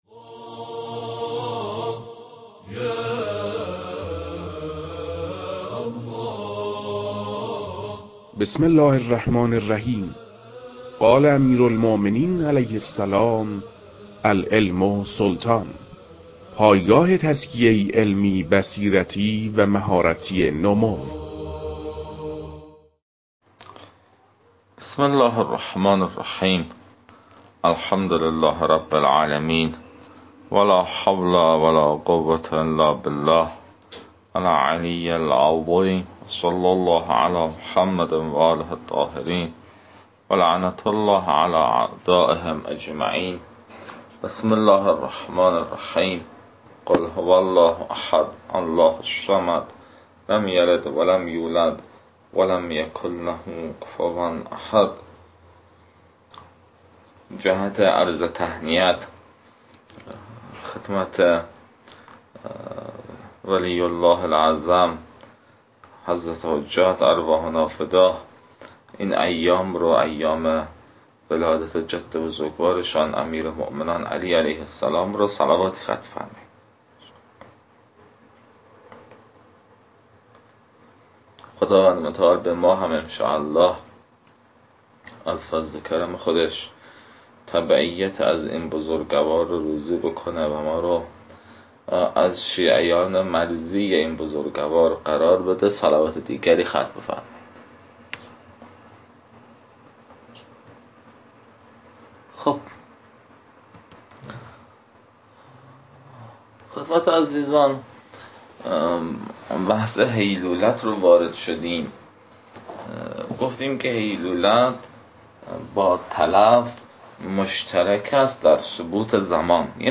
در این بخش، فایل های مربوط به تدریس مباحث تنبیهات معاطات از كتاب المكاسب متعلق به شیخ اعظم انصاری رحمه الله